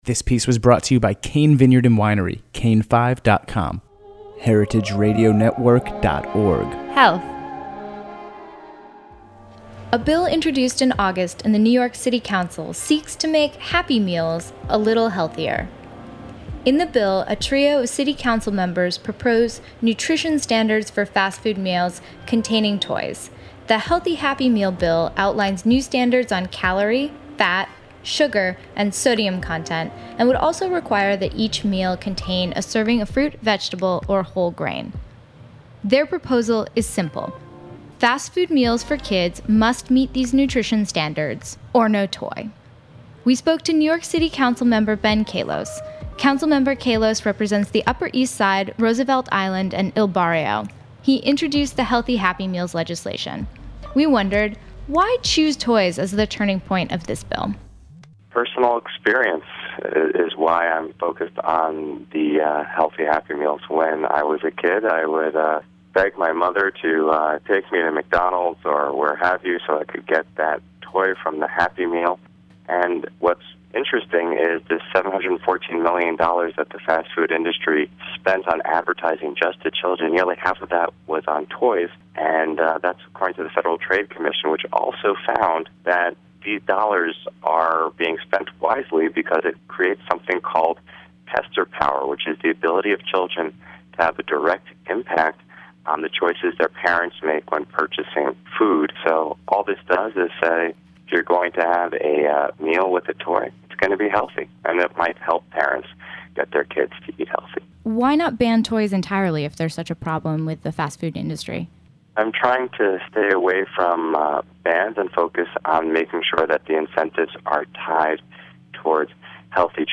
Recently City Council Member Ben Kallos has introduced legislation to create a healthier happy meal. Council Member Kallos and Marion Nestle comment on the new bill.